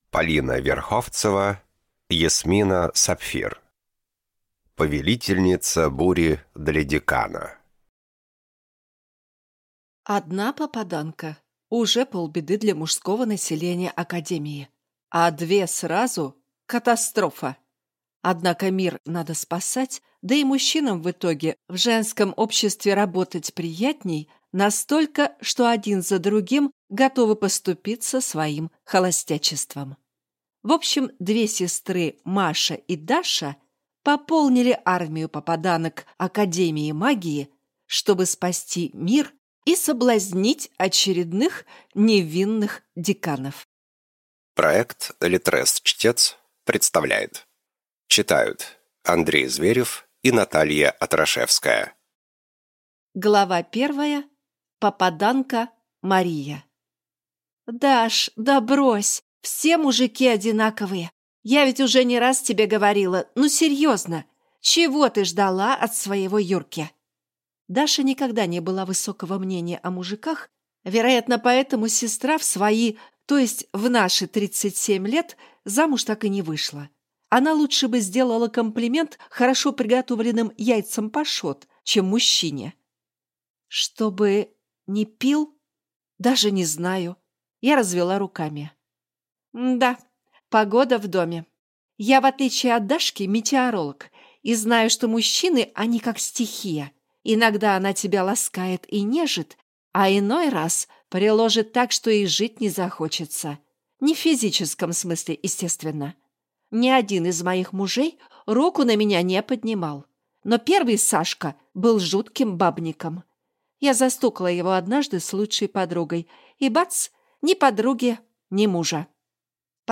Аудиокнига «Особенности разведения небожителей».